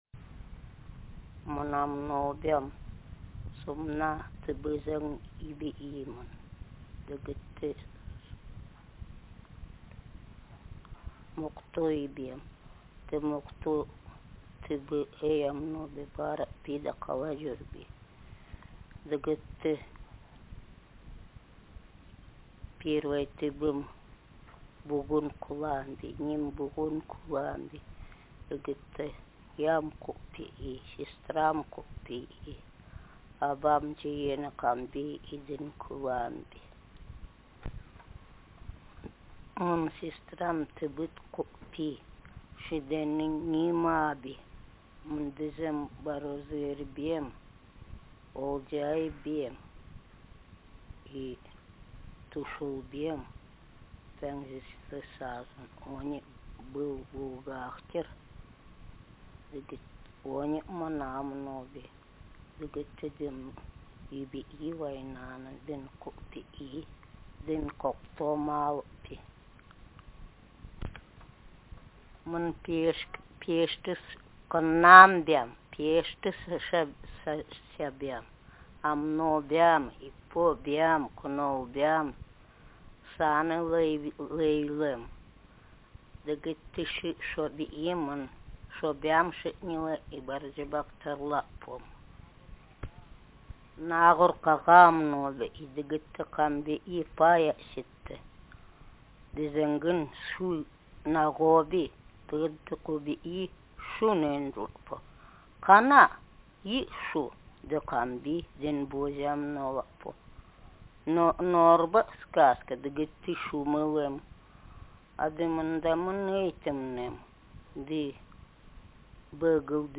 Speaker sex f Text genre personal narrative